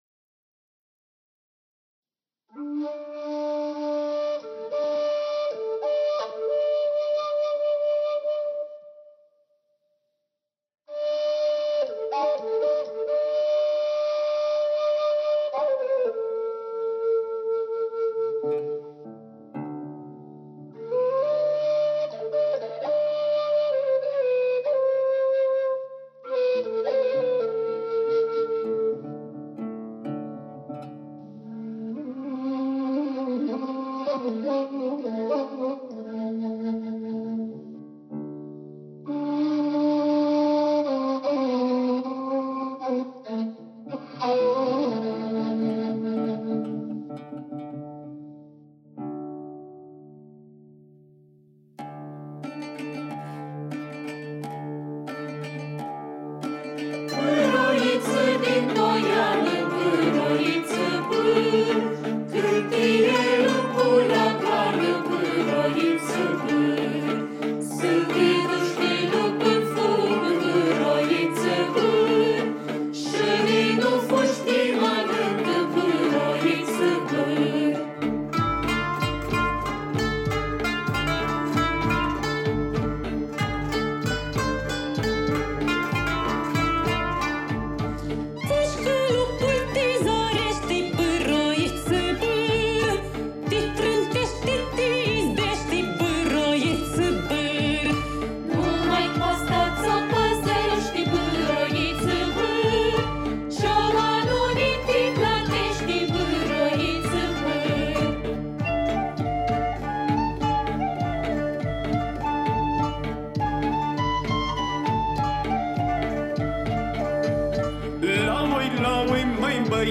cântecul vocal de joc cu tematică pastorală „Bâr
Melodia „Bâr, oiță din poiană” este un cântec vocal de joc cu tematica pastorală care face parte din repertoriul folcloric aflat în uz în Bucovina istorică (sub dominație austro-ungară), cules acum mai bine de 100 de ani de către Alexandru Voevidca (1862-1931).
Melodia Bâr, oiță din poiană a fost abordată într-o manieră specifică Ansamblului de muzică veche românească „Floralia”, prin intervenții solistice instrumentale și vocale și dialog între grupele de voci feminine și masculine. Aranjamentul instrumental a încercat să fie unul cât mai simplu, care să evidențieze linia melodică modală, cu ușoare inflexiuni orientale. A fost preferat acompaniamentul armonic și ritmic de tip taraf și au fost inserate scurte interludii instrumentale între strofele melodice.
vioară
caval, fluier și voce
Frumos – lăută și voce – solo II
violă
percuție
kanon